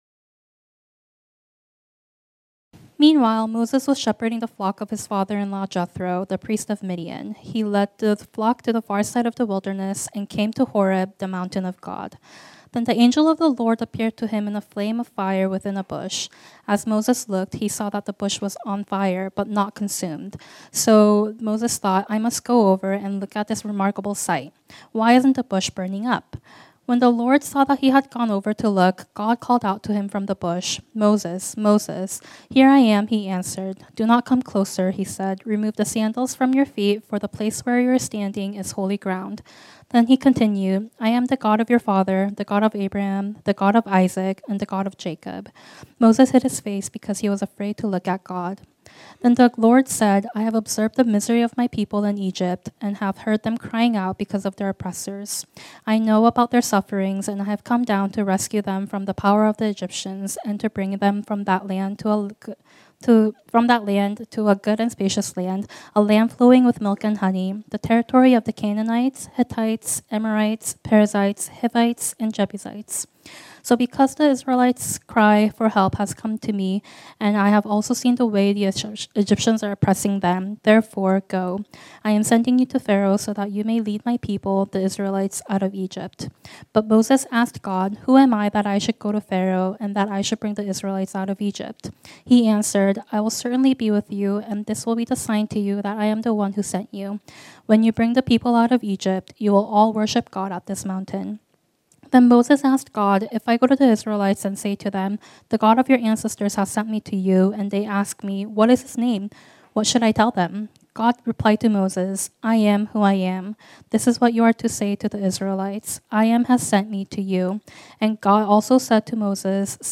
This sermon was originally preached on Sunday, January 19, 2025.